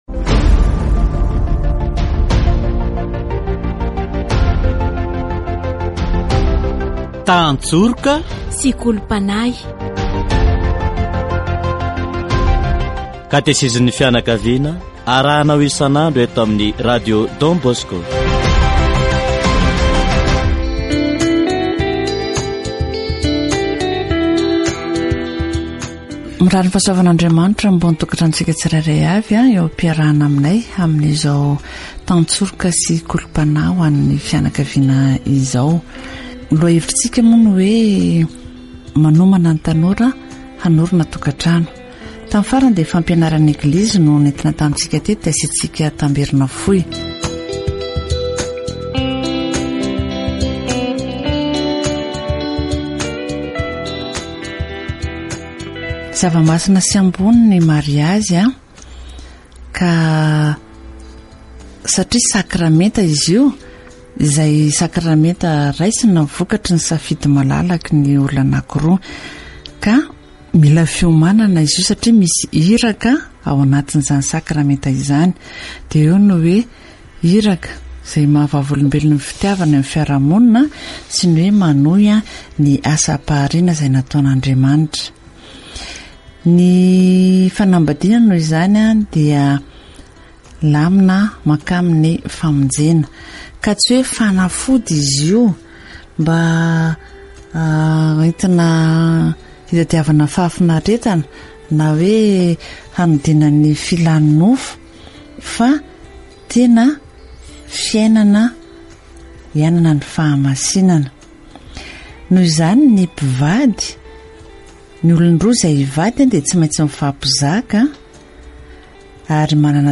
Katesizy momba ny tanora miomana hiditra tokan-trano